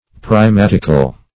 Primatical \Pri*mat"ic*al\, a. Of or pertaining to a primate.